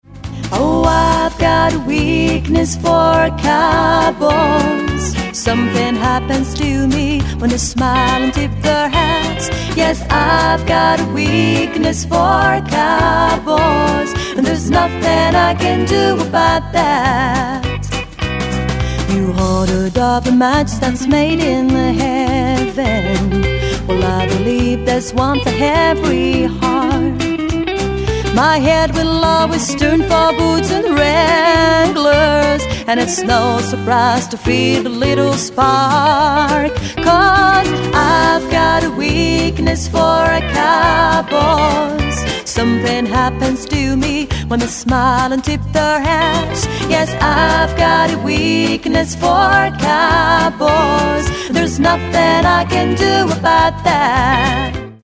Chant, Harmonies
Basse, Programmation Batterie, Harmonies
Guitare Acoustique & Electrique, Programmation Batterie
Guitare Additionnelle